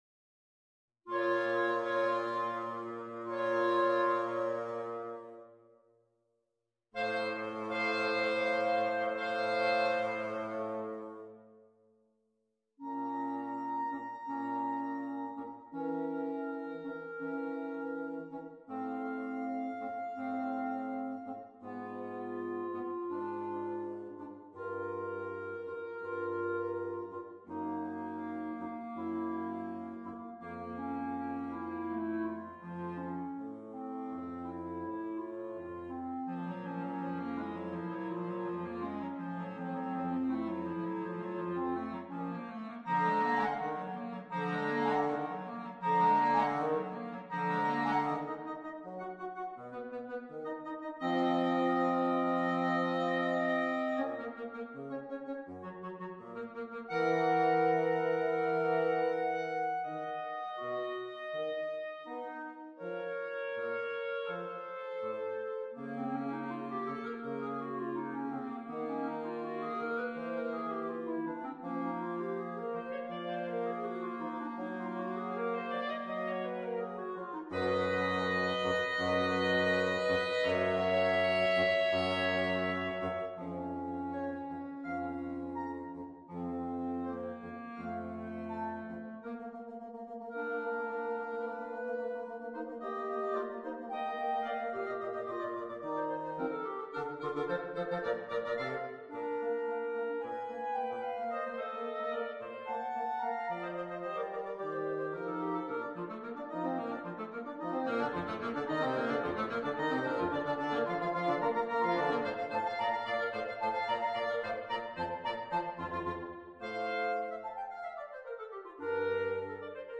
OUVERTURE PER 2 CLARINETTI E FAGOTTO (o clarinetto basso)
solenne, drammatica e vivace